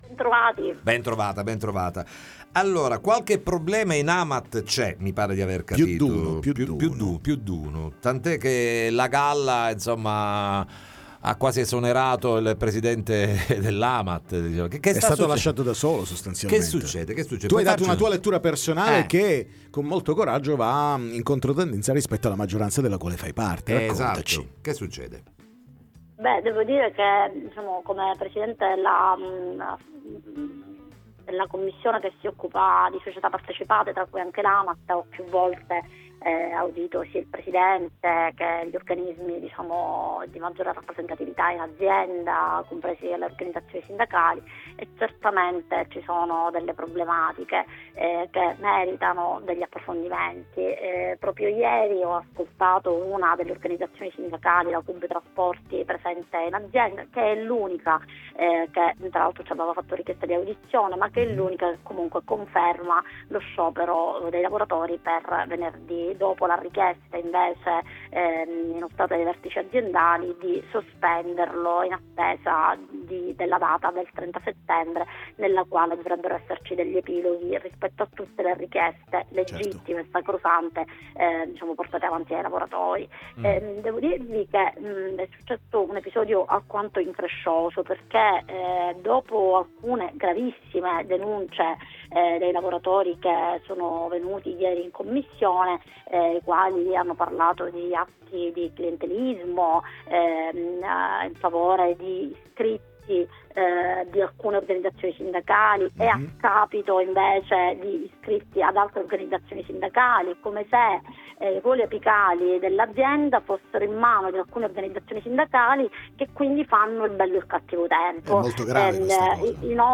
Denunce gravi all’AMAT, Sabrina Figuccia chiama a raccolta i lavoratori, ne parliamo con lei ai nostri microfoni